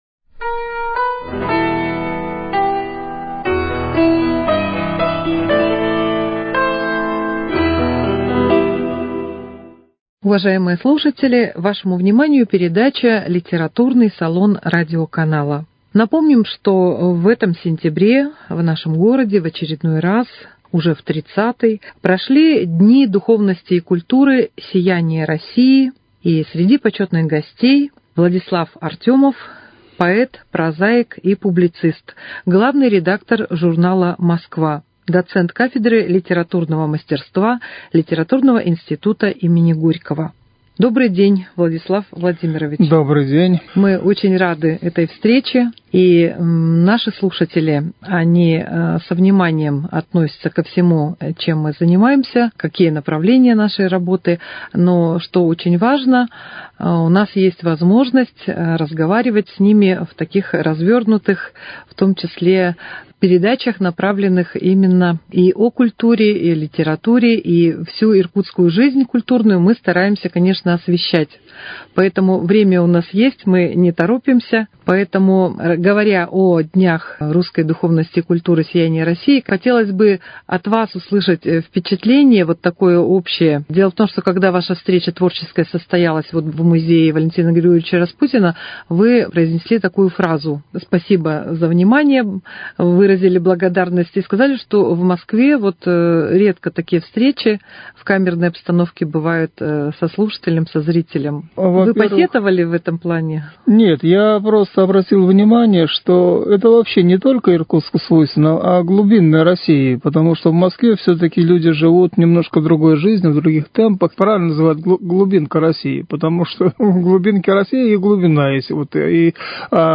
Литературный салон: Беседа с поэтом, прозаиком и публицистом